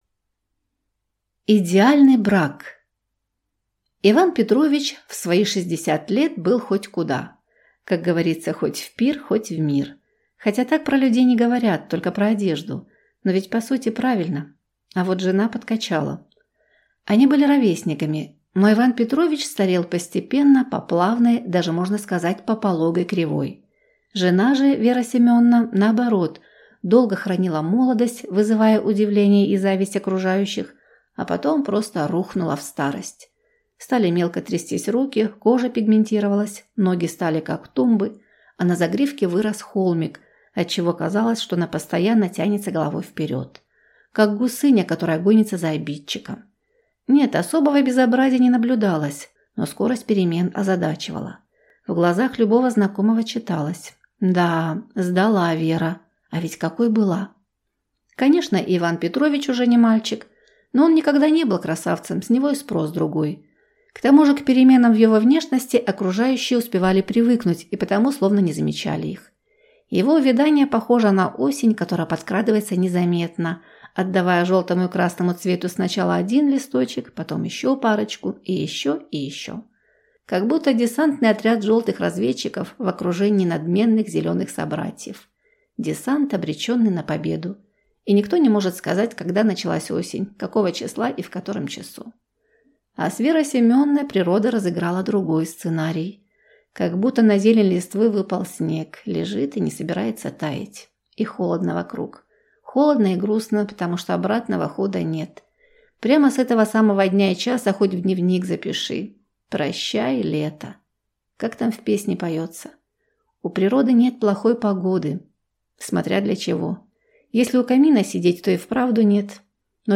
Аудиокнига Счастье ходит босиком | Библиотека аудиокниг